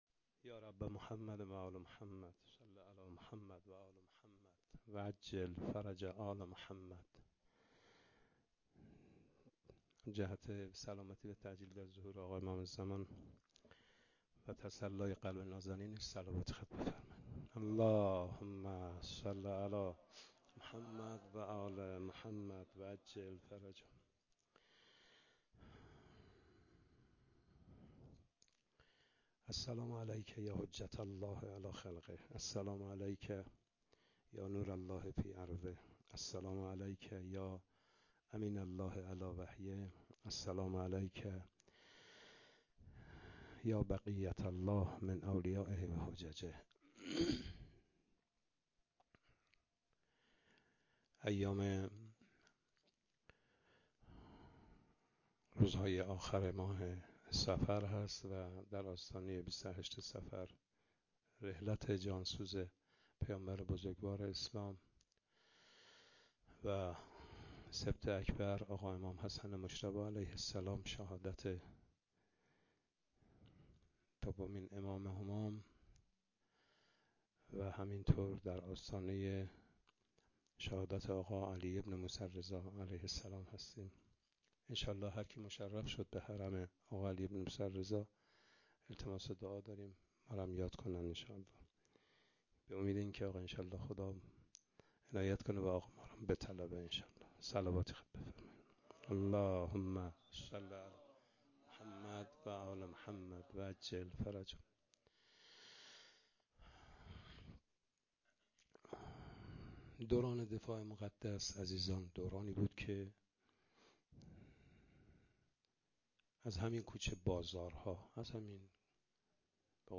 01-sokhanrani.mp3